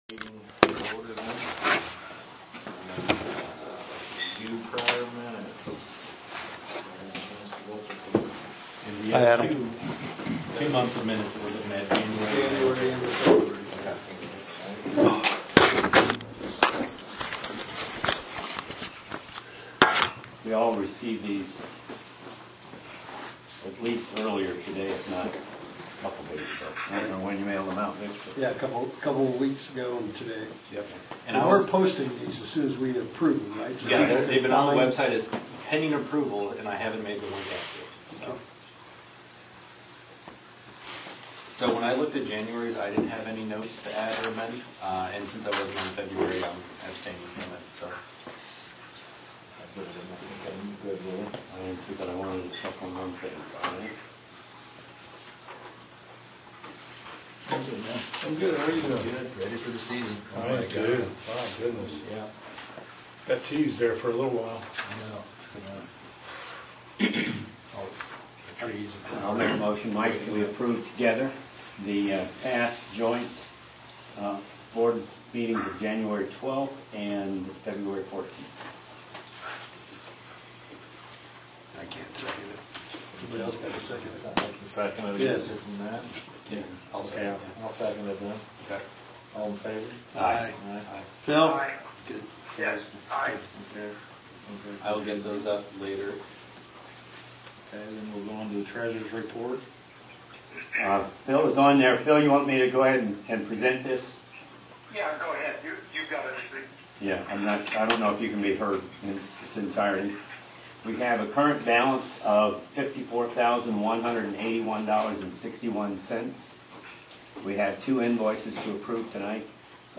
Brown County Regional Sewer District (BCRSD) Board Meeting Notes, Tues Mar 14, 2023.